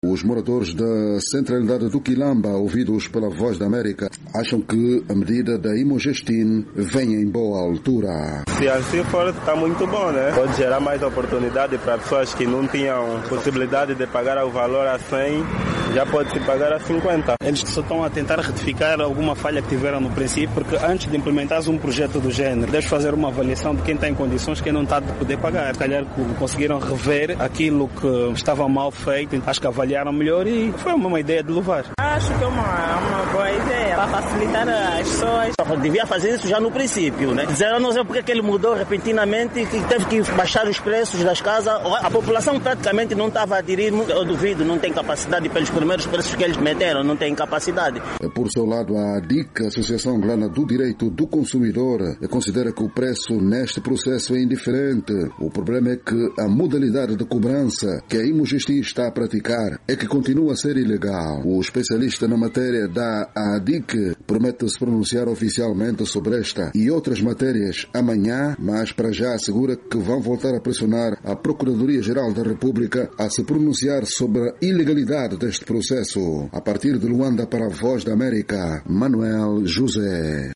Os moradores da centralidade do Kilamba ouvidos pela VOA mostraram-se contudo satisfeitos.